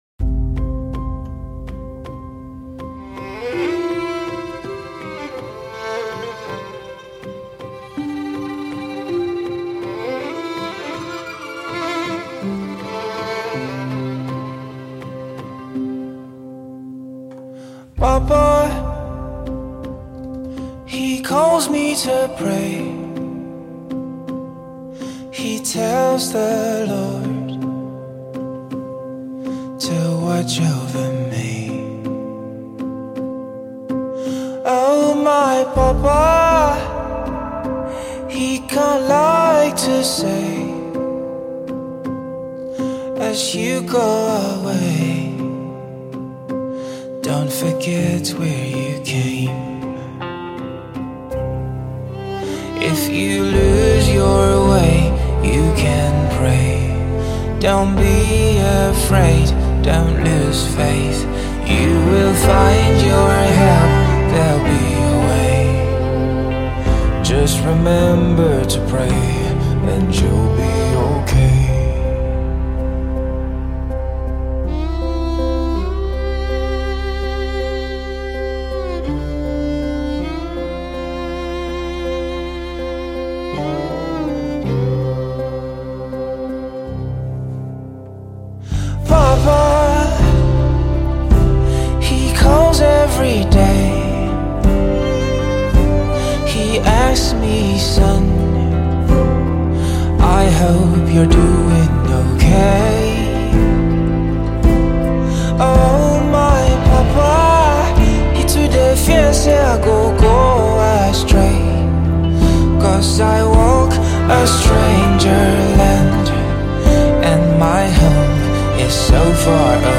Folk singer